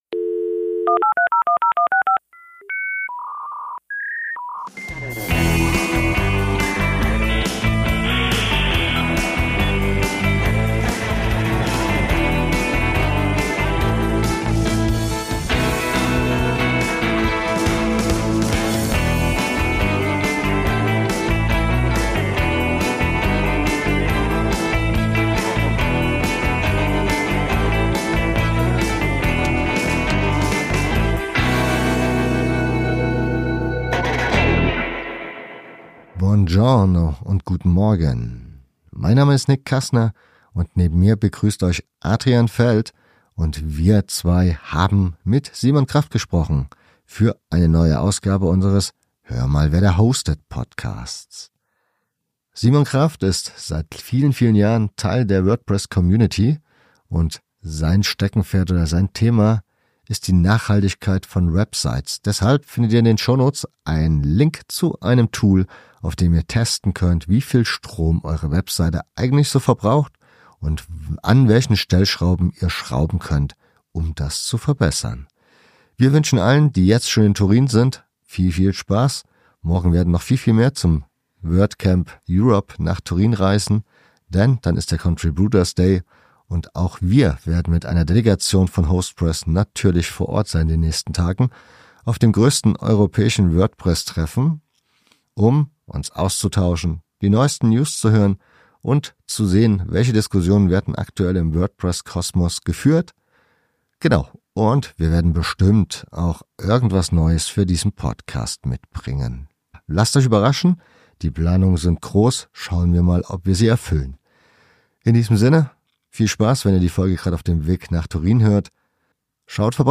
Grund genug, sich endlich mal mit ihm zu unterhalten.